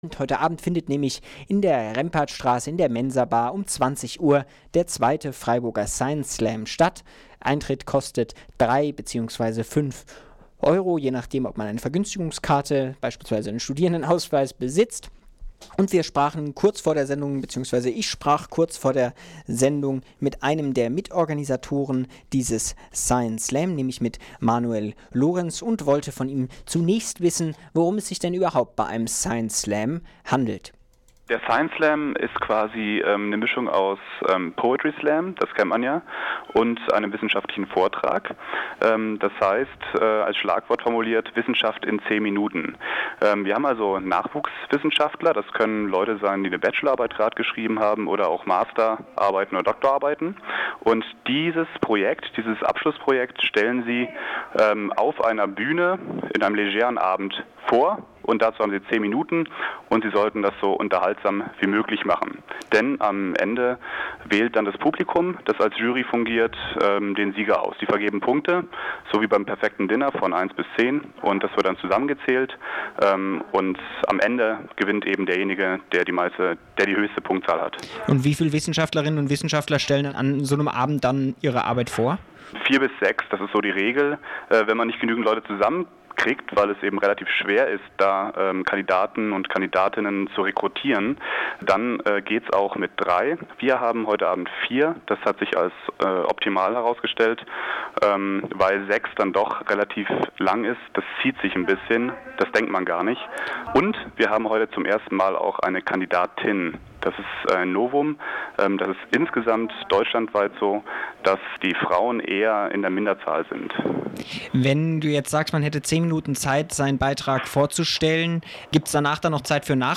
2. Freiburger Science Slam! - Gespräch mit einem Mitorganisator